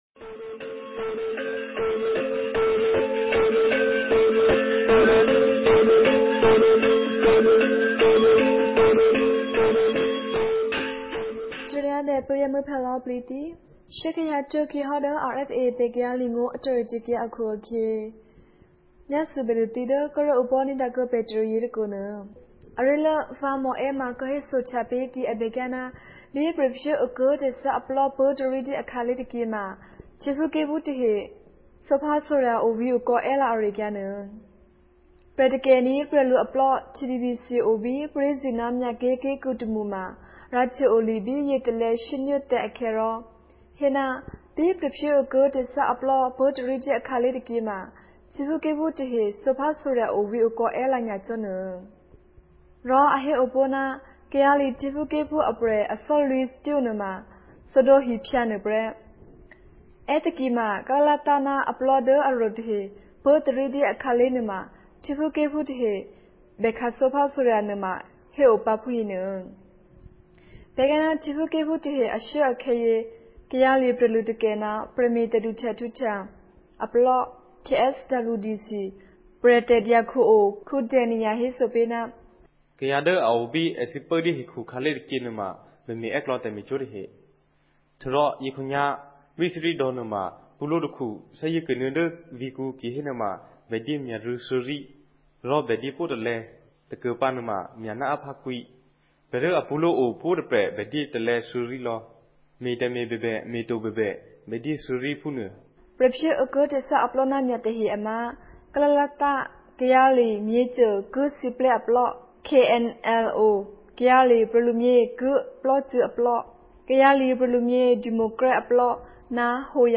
ကရင်နီဘာသာ အသံလြင့်အစီအစဉ်မဵား